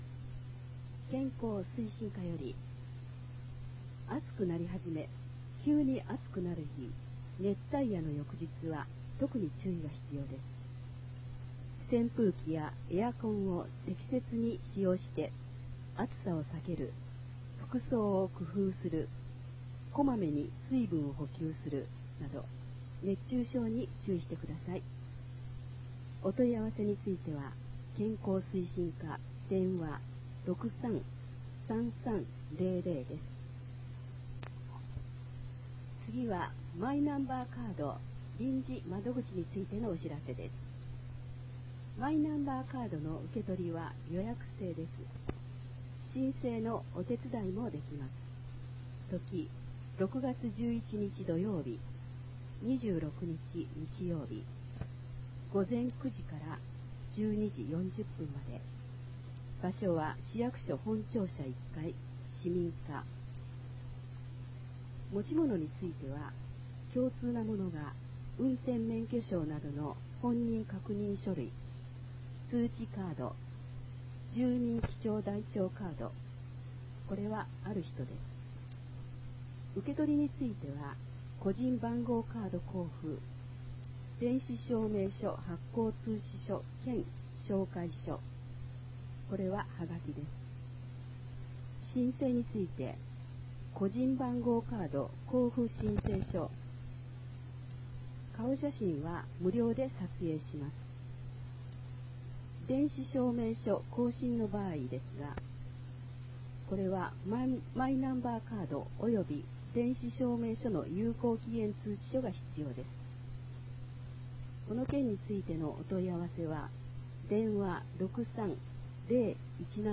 平成29年8月号から、ボランティア団体「愛eyeクラブ」の皆さんの協力により、広報ながくてを概要版として音声化して、ホームページ上で掲載しています。
音声ファイルは、カセットテープに吹き込んだものをMP3ファイルに変換したものです。そのため、多少の雑音が入っています。